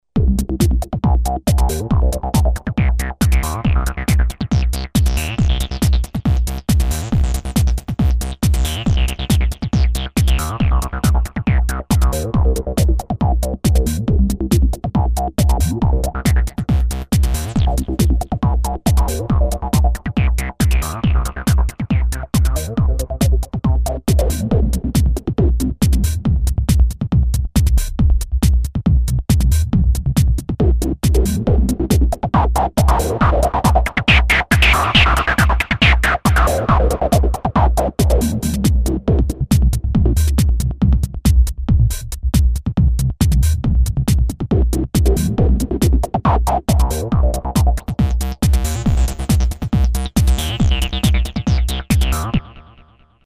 Mostly DJ-Live oriented in the LOOP FACTORY series grooveboxes based on AN analog physical modeling synthesis and sampled percussions.
preset pattern